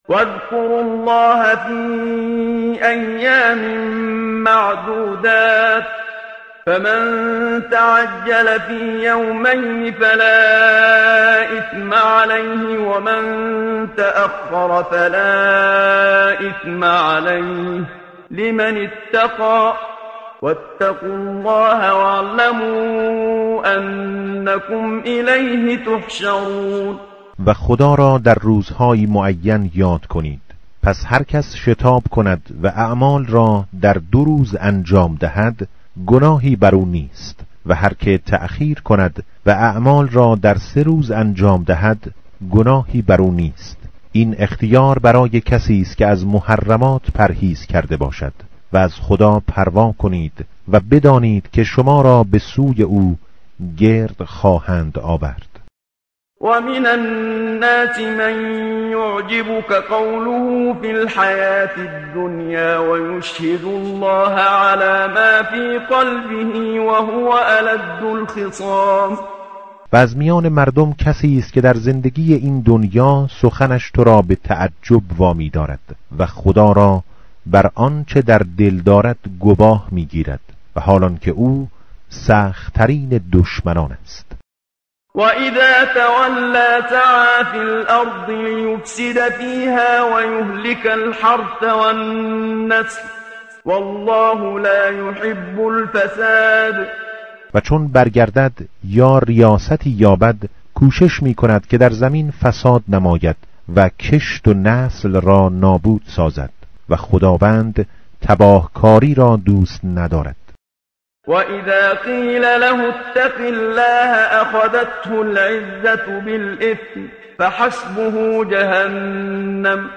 متن قرآن همراه باتلاوت قرآن و ترجمه
tartil_menshavi va tarjome_Page_032.mp3